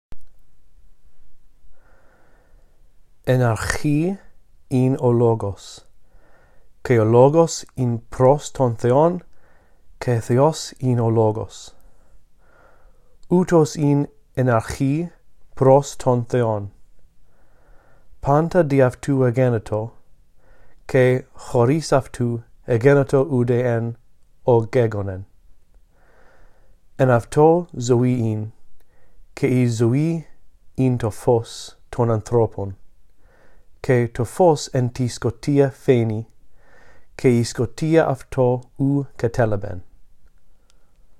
1. Listen to me read all of John 1:1-5, following along in the text below.